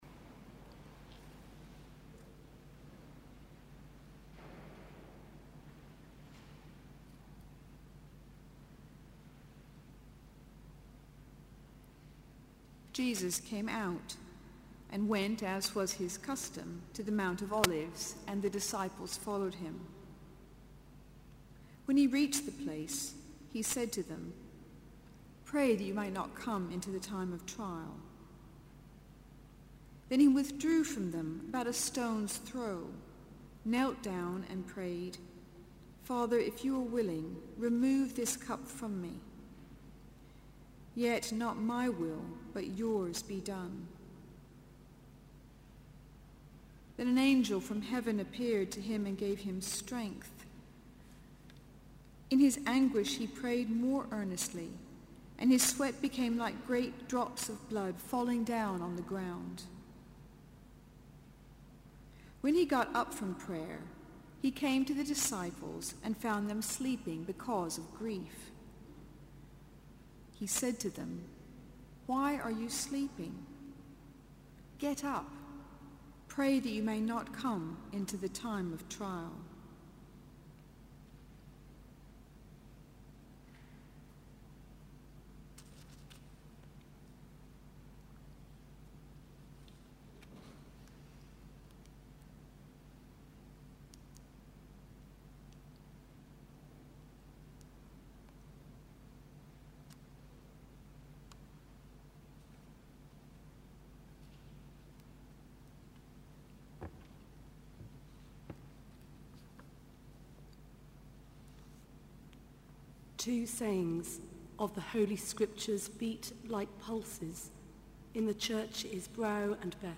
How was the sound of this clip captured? Friday 18th April 2014 Service: Three Hours Devotion Listen